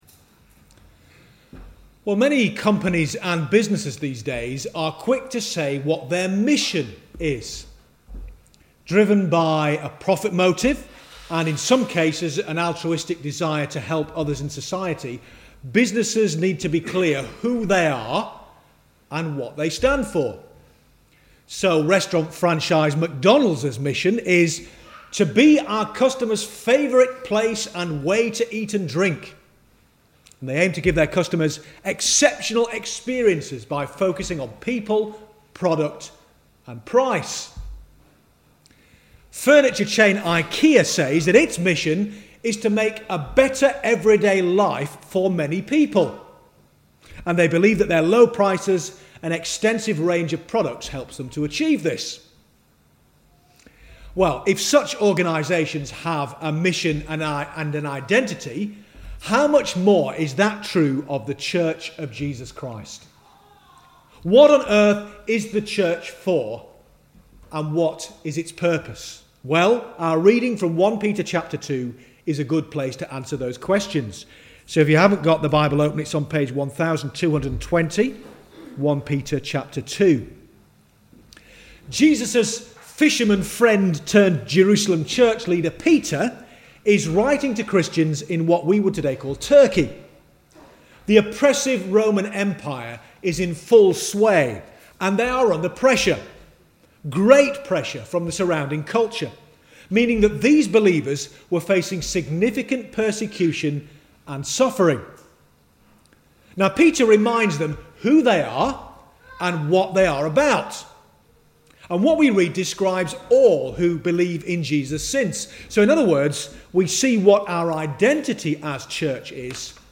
2 April 2017 – Annual Parochial Church Meeting Sermon/Report